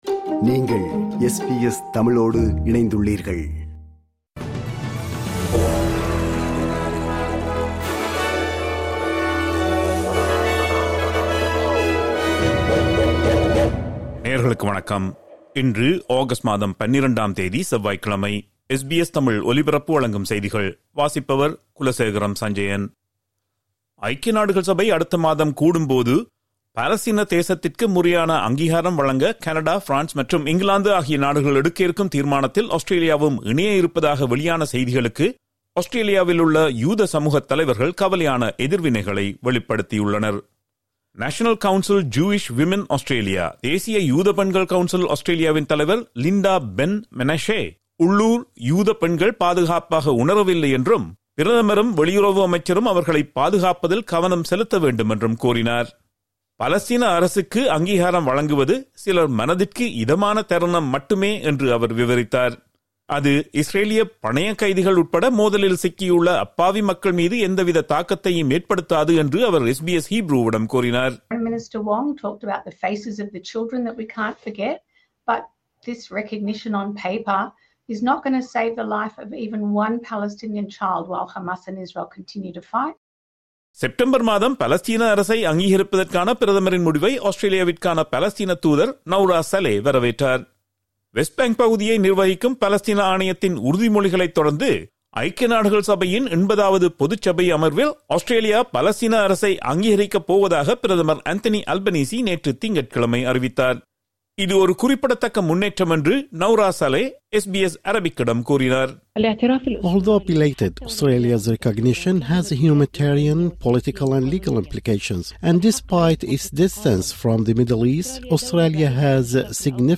SBS தமிழ் ஒலிபரப்பின் இன்றைய (செவ்வாய்க்கிழமை 12/08/2025) செய்திகள்.